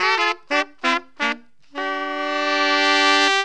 HORN RIFF 26.wav